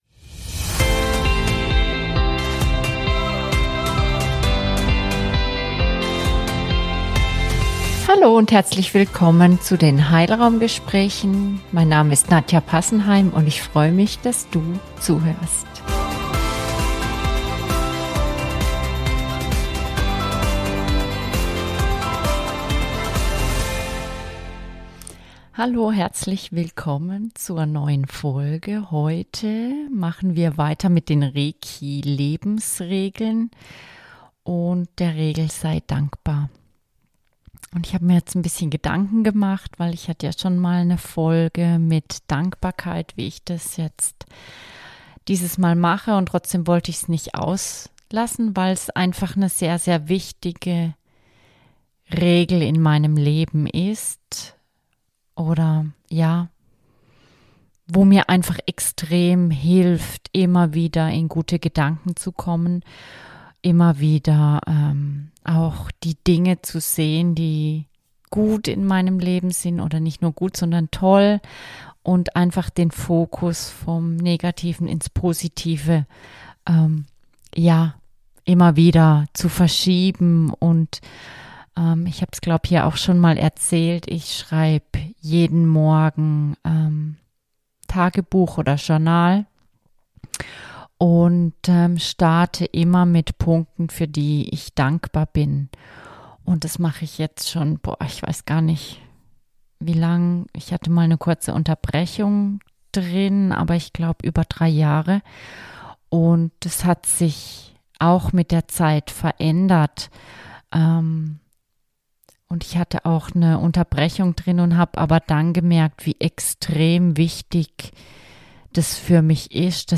In dieser Folge tauche ich erneut in die Welt der Reiki Lebensregeln ein, dieses Mal mit einer Meditation zum Thema „sei dankbar“.